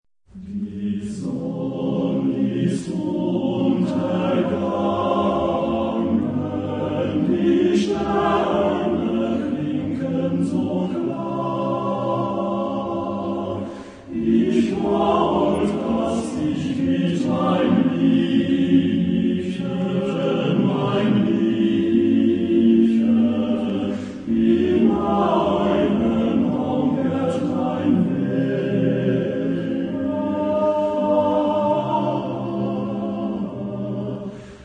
Genre-Style-Forme : Profane ; Populaire
Type de choeur : TTBB  (4 voix égales d'hommes )
Tonalité : ré bémol majeur